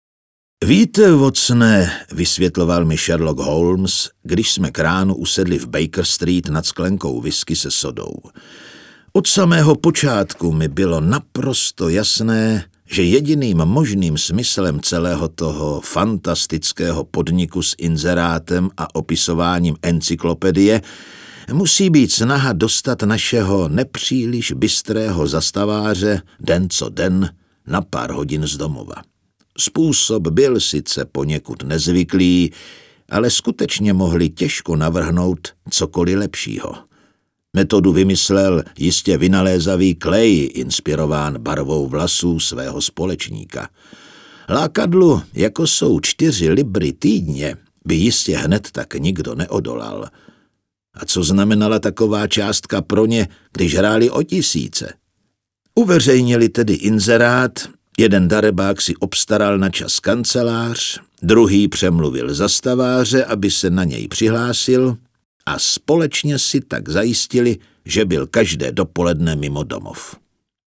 Audioknihy:
Zdeněk Junák je český herec.